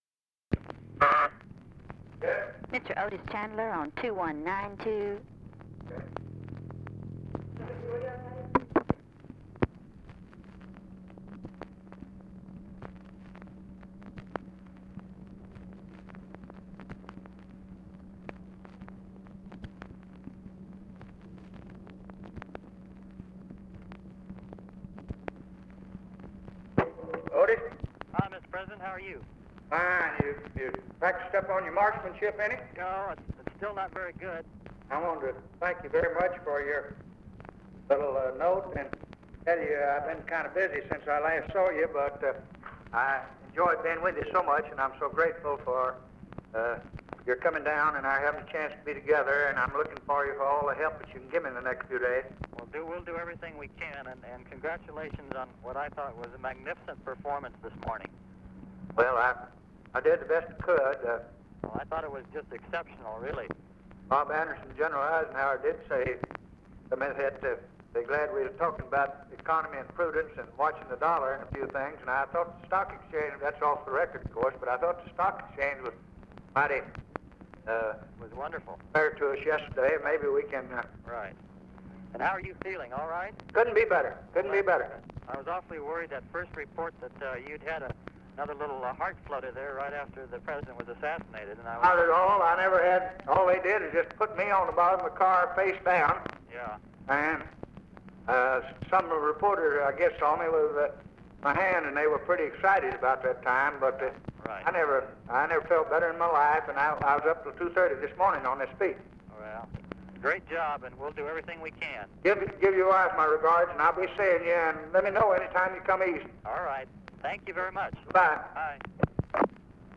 Conversation with OTIS CHANDLER, November 27, 1963
Secret White House Tapes